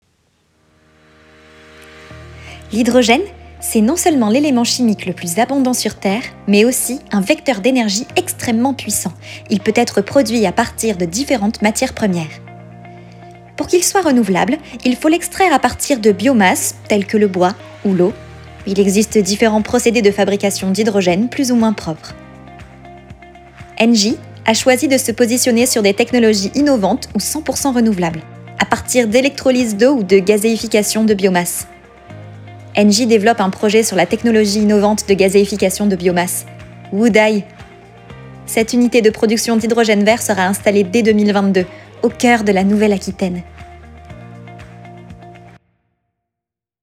Voix off
Pub voix Engie
- Soprano